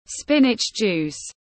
Nước ép rau chân vịt tiếng anh gọi là spinach juice, phiên âm tiếng anh đọc là /ˈspɪn.ɪtʃ ˌdʒuːs/
Spinach juice /ˈspɪn.ɪtʃ ˌdʒuːs/